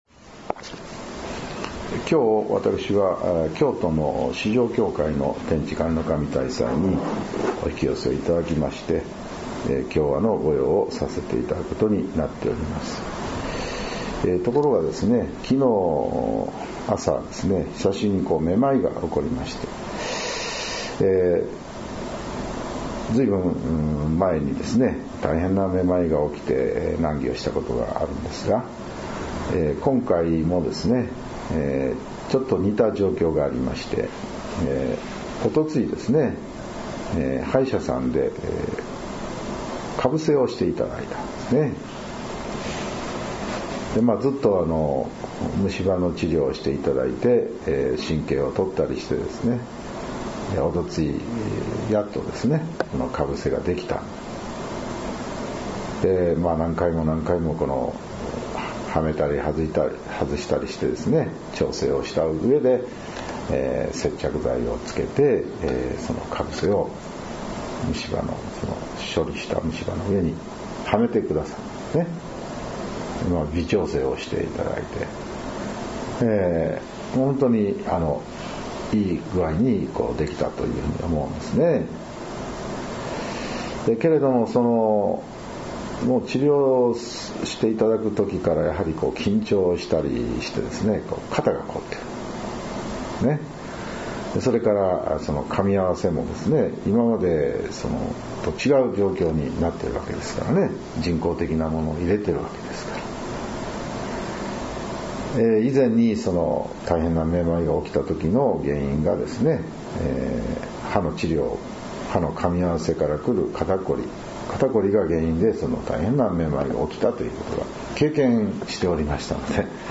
朝の勢祈念時のお話を音声ブログとして、聞くことができます。